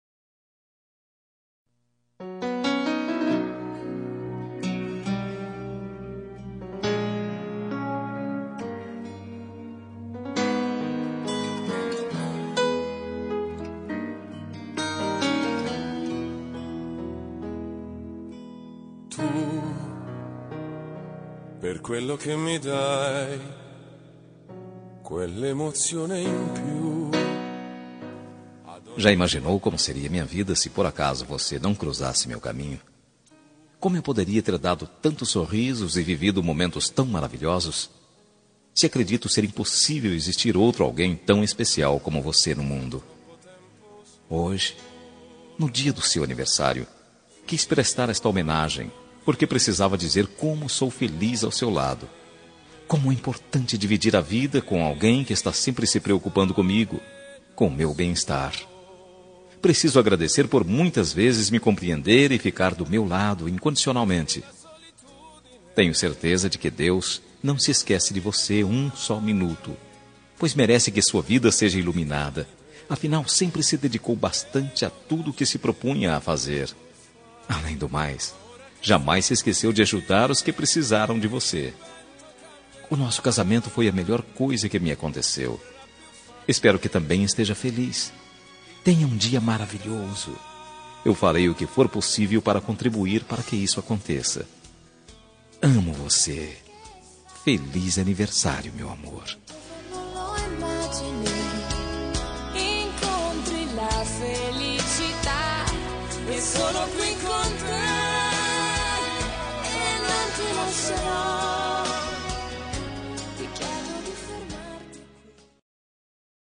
Telemensagem Aniversário de Esposa – Voz Masculina – Cód: 1132